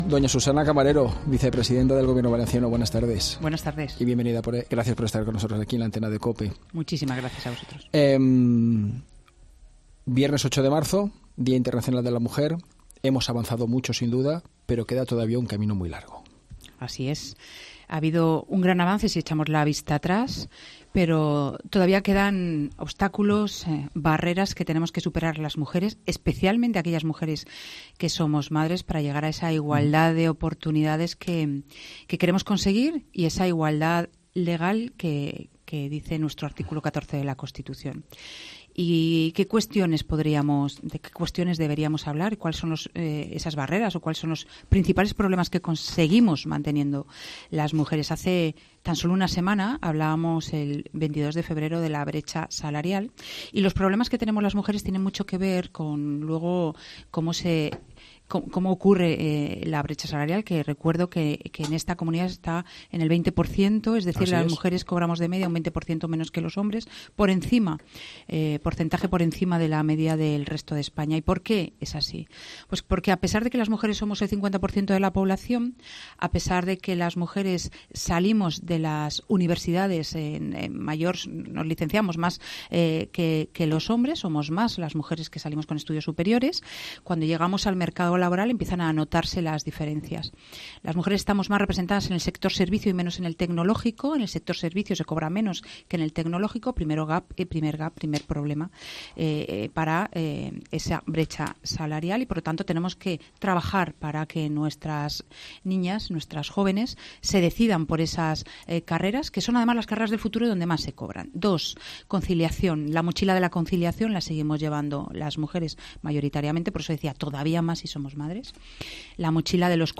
Mediodía COPE MÁS Valencia | Entrevista a Susana Camarero